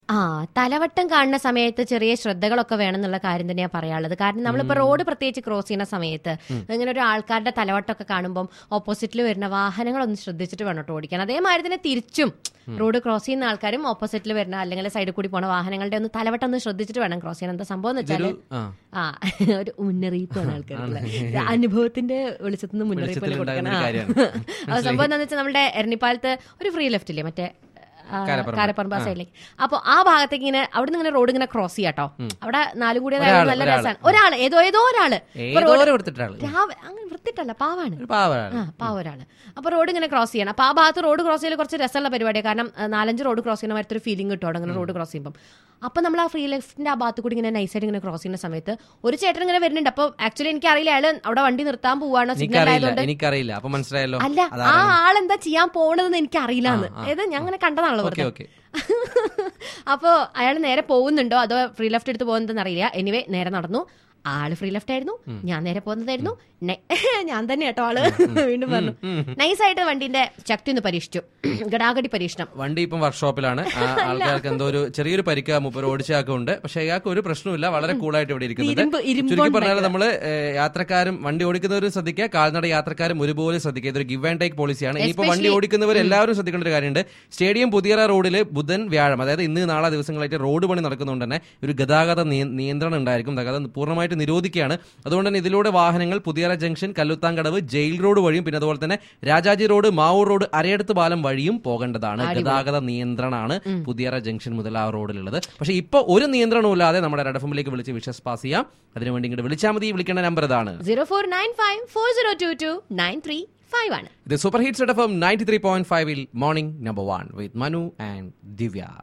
TRAFFIC UPDATE